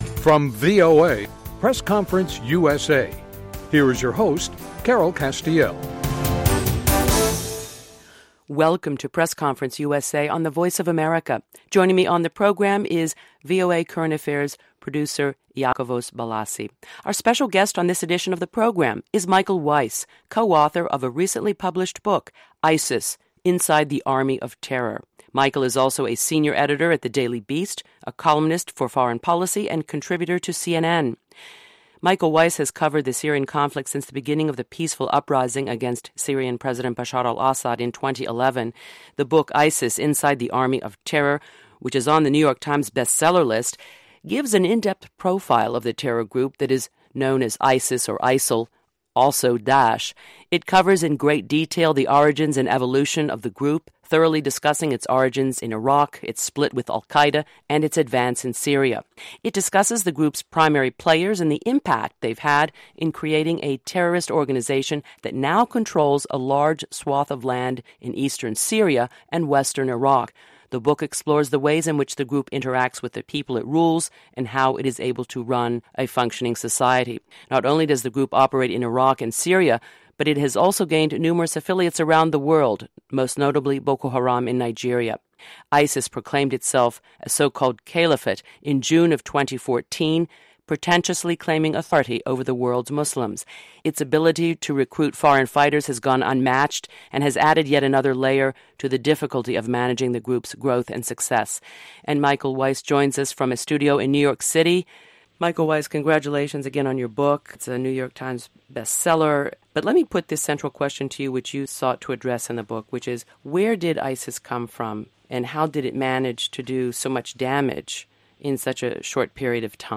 DISCLAIMER: This interview was recorded before the terror attacks in Paris.